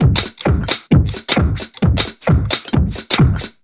Index of /musicradar/uk-garage-samples/132bpm Lines n Loops/Beats
GA_BeatDSweepz132-01.wav